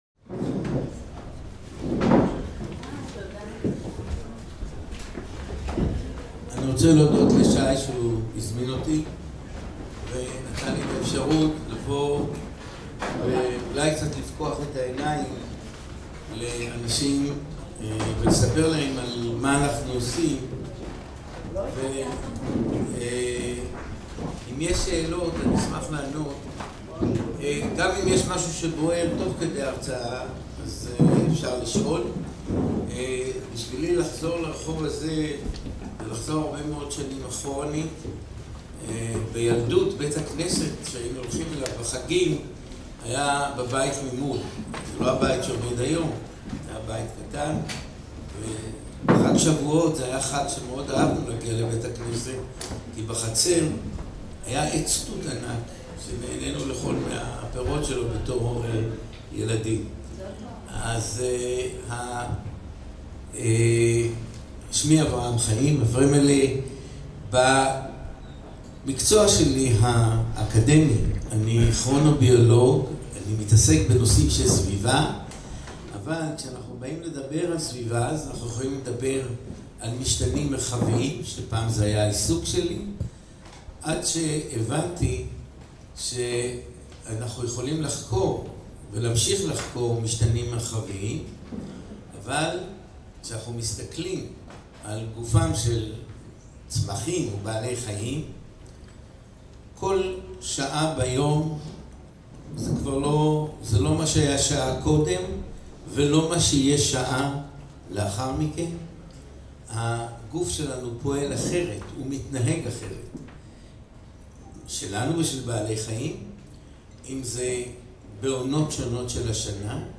הרצאה